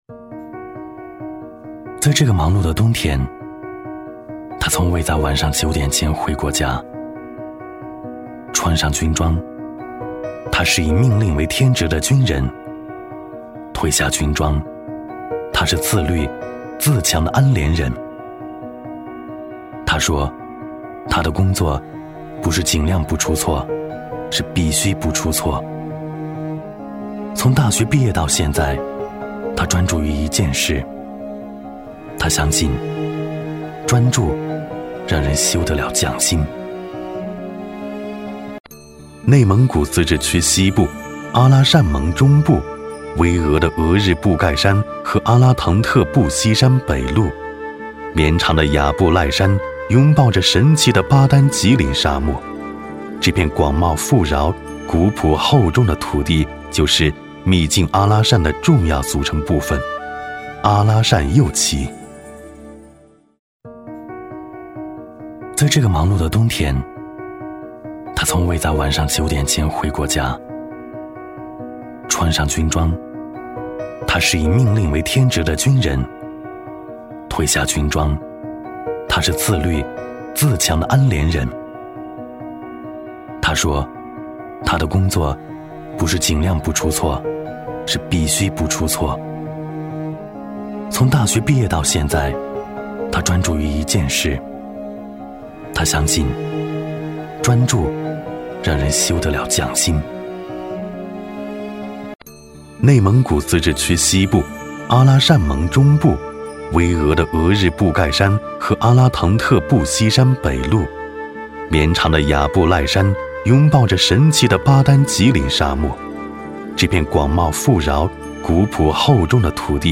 男S356 国语 男声 纪录片-香港马会-磁性、讲述 低沉|大气浑厚磁性|沉稳